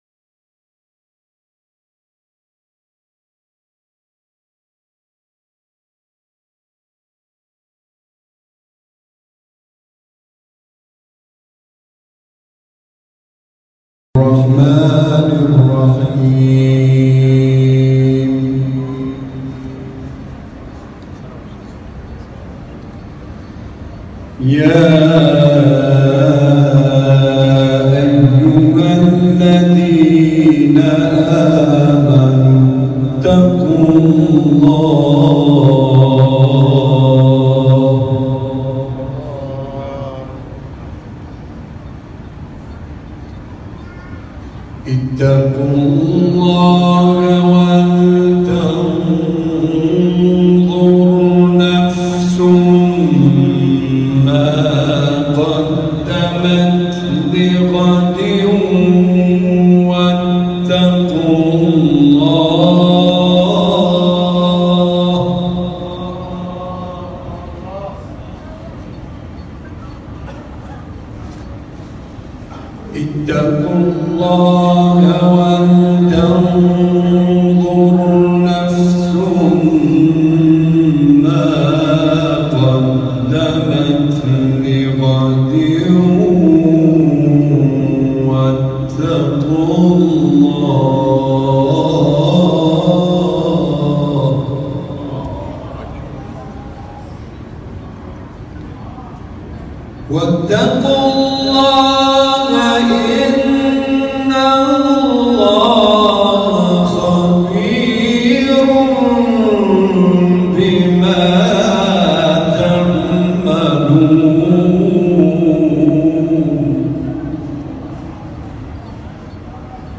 جدیدترین تلاوت
فایل صوتی تلاوت سوره حشر